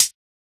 UHH_ElectroHatB_Hit-13.wav